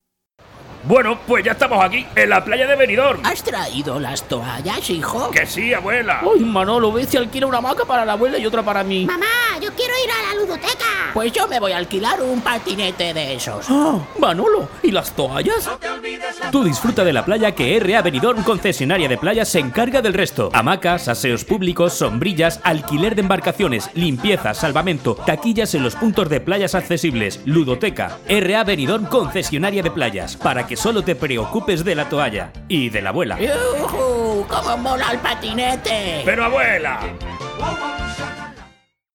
Sprechprobe: Werbung (Muttersprache):
Different records, imitations, voice actor Young Voice-Adult-Senior, Soft or Hard Commercial Voice, a little bit of everything.